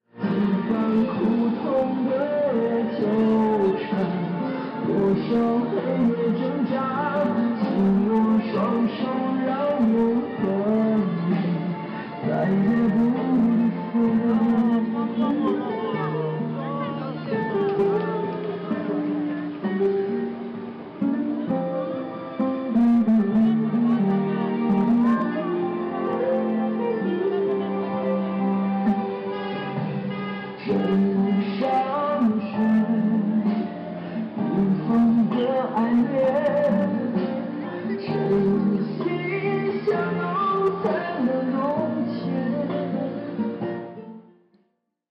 Karaoke
Karaoke in a small park in Chongqing, China